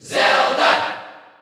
Zelda_Cheer_Dutch_SSBU.ogg